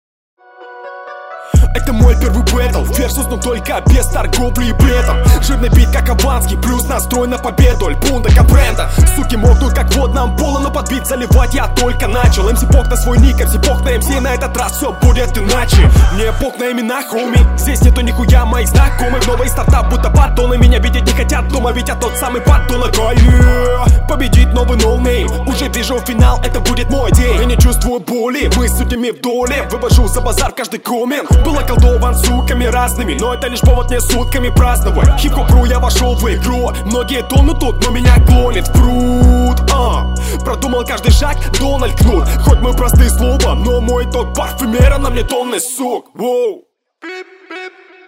Эффекты лишние и текст недостаточно проработан.
Подача не цепляет, в текст не погружаешься